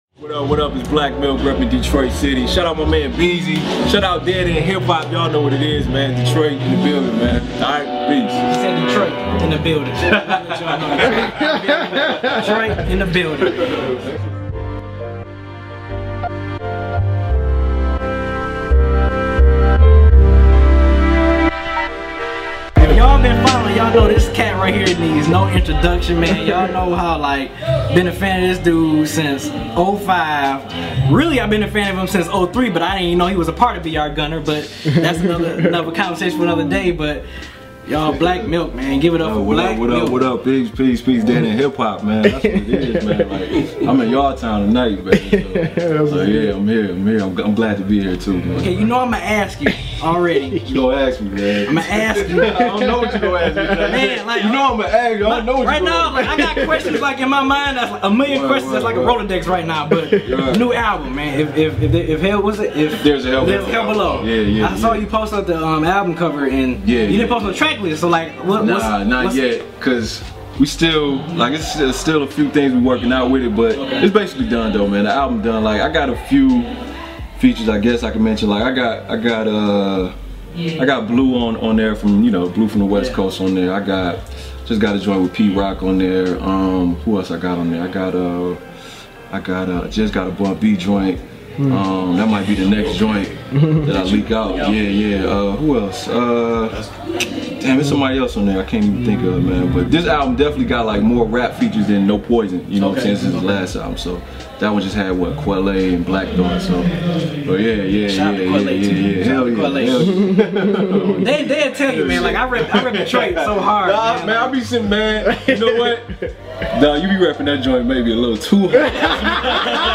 Black Milk Interview with Dead End Hip Hop